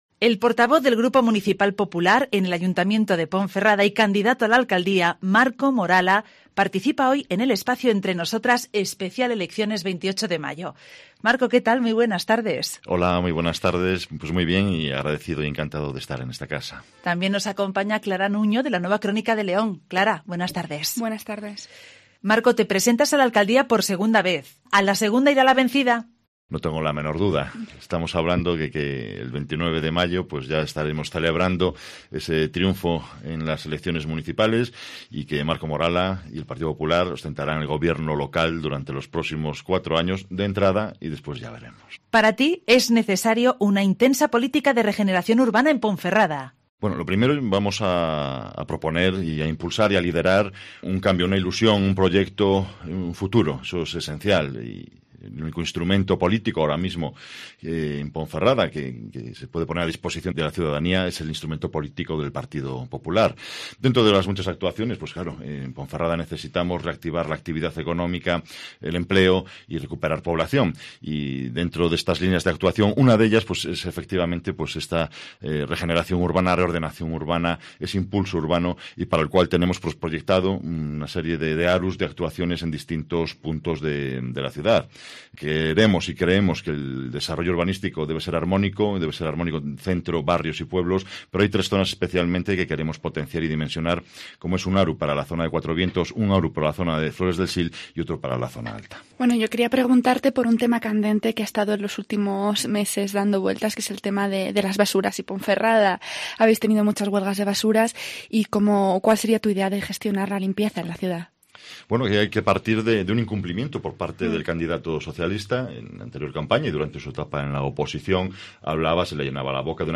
El portavoz del Grupo Municipal Popular en el Ayuntamiento de Ponferrada y candidato a la alcaldía, Marco Morala, paricipa hoy en el espacio “Entre Nosotras especial elecciones 28 M”.